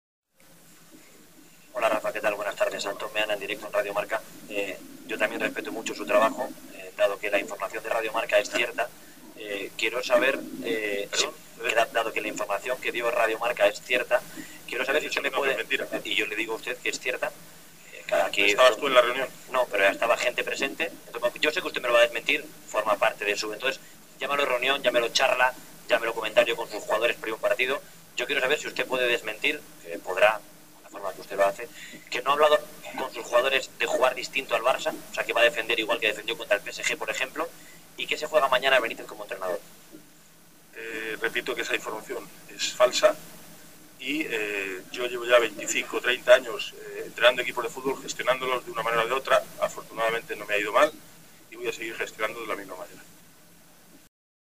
Este día en la conferencia de prensa previa al Clásico de mañana ante el Barcelona, Benítez fue directo y negó dicha reunión con los futbolistas del plantel, al menos así le respondió al periodista que le preguntó sobre el posible hecho.
Click en Play para escuchar la pequeña discusión entre el entrenador merengue y el periodista de ‘Radio Marca’.
ConferenciaPrensaRafaBenítez.mp3